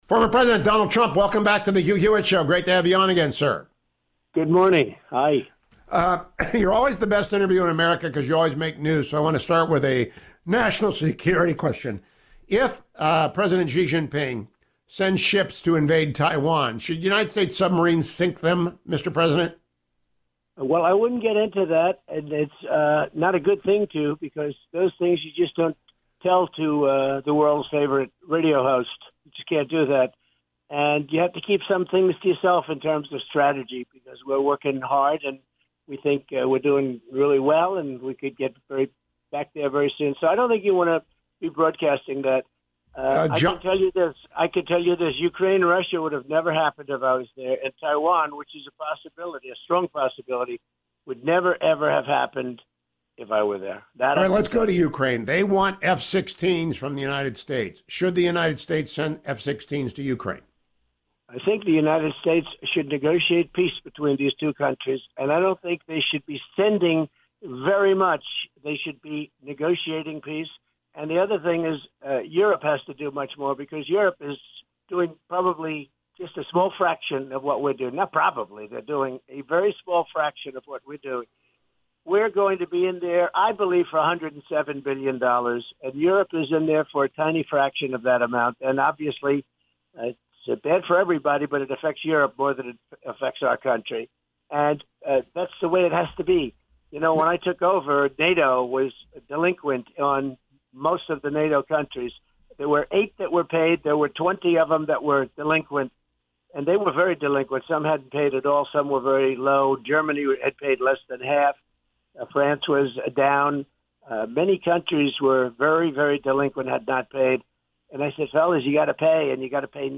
Former President Donald Trump joined me this morning: